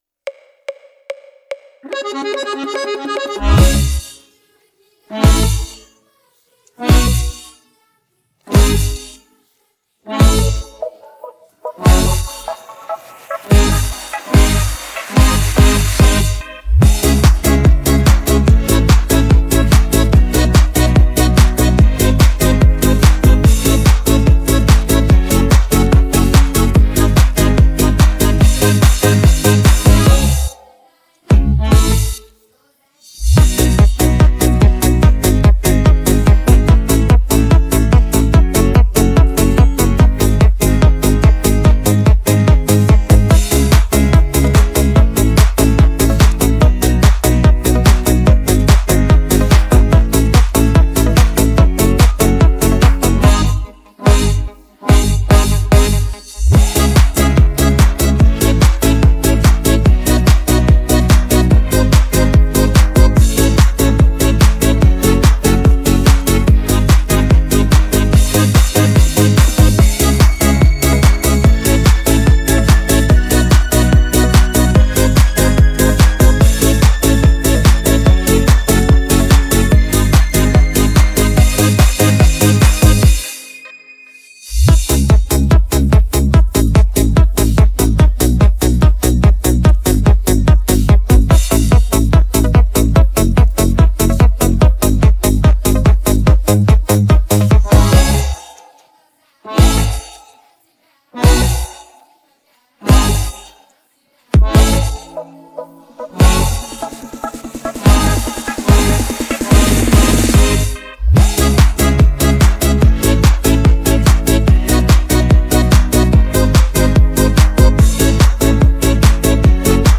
Лиричные песни о любви с элементами попа и шансона.